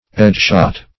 edgeshot - definition of edgeshot - synonyms, pronunciation, spelling from Free Dictionary Search Result for " edgeshot" : The Collaborative International Dictionary of English v.0.48: Edgeshot \Edge"shot\, a. (Carp.) Having an edge planed, -- said of a board.